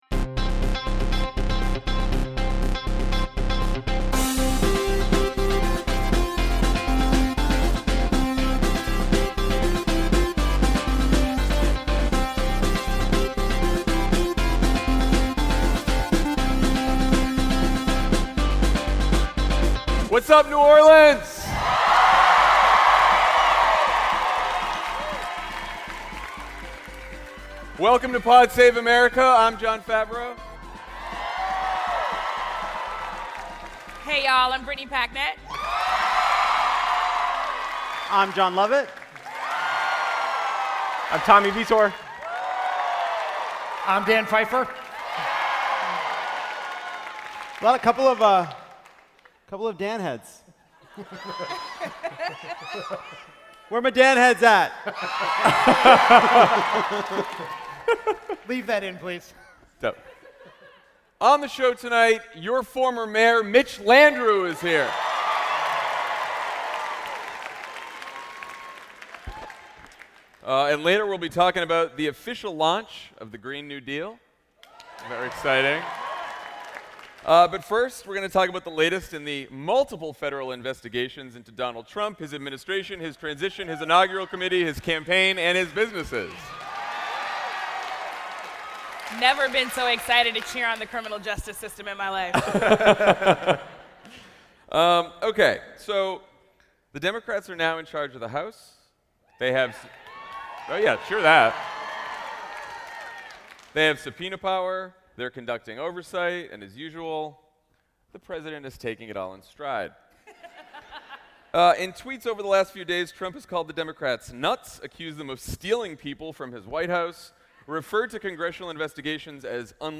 Trump lashes out as investigators close in, Democrats introduce a Green New Deal, and former Mayor Mitch Landrieu joins Jon, Jon, Tommy, Dan, and Brittany Packnett live on stage in New Orleans.